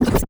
Robotic Back Button 5.wav